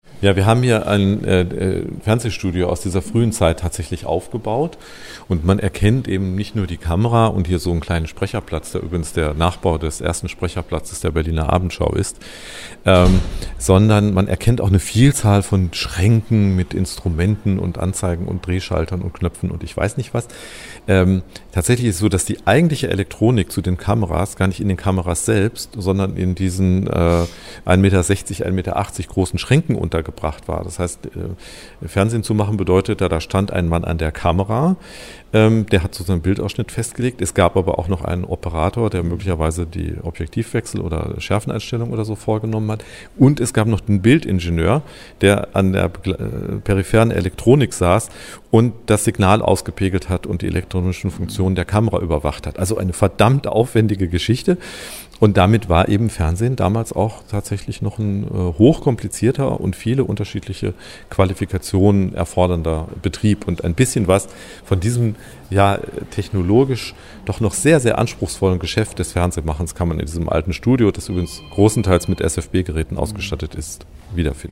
Was: Rundgang durch die Abteilung Nachrichtentechnik